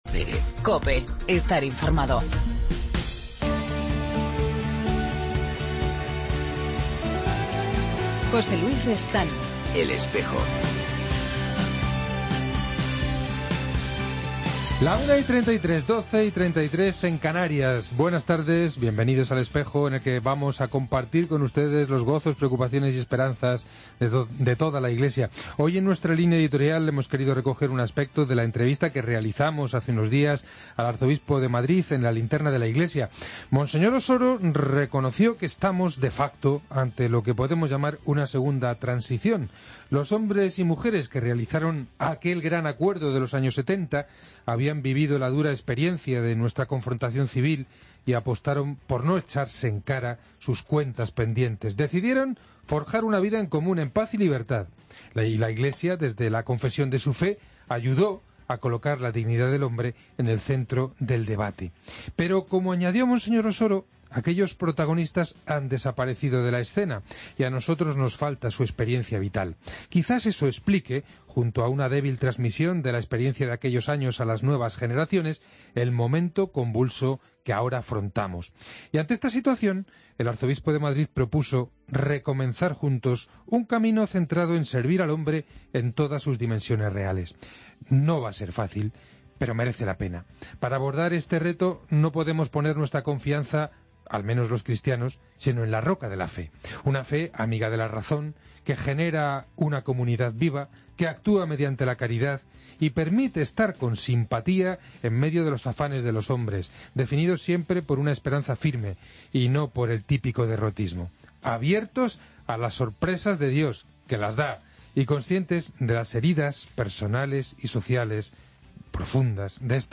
AUDIO: Como cada martes, abrimos espacio en El Espejo para el testimonio y la presencia de la Vida Religiosa. Ha estado con nosotros el carmelita...